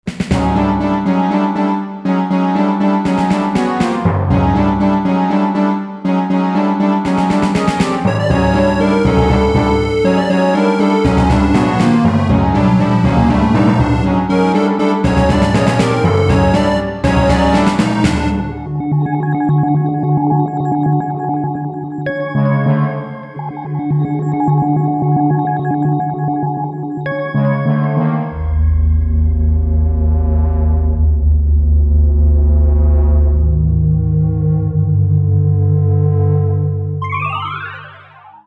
Very 70's synth sound.